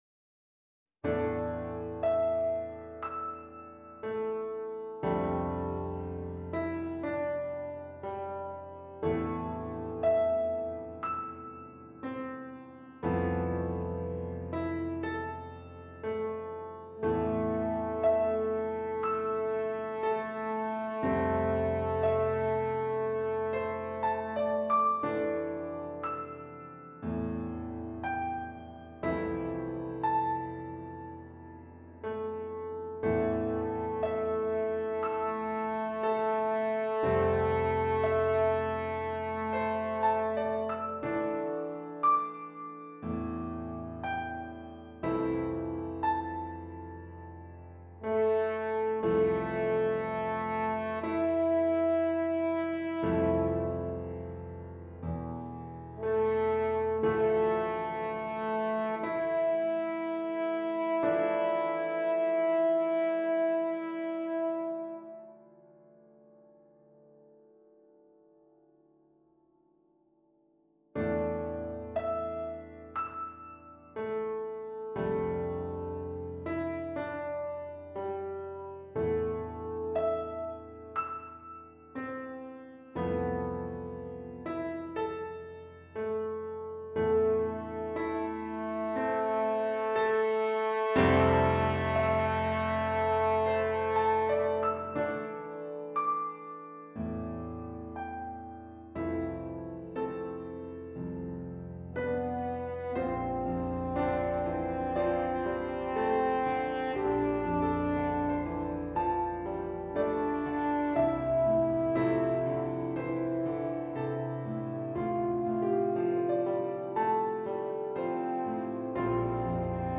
Elementary French Horn
Interplanetary Etudes: The Rover Suite, by Mari Alice Conrad for French Horn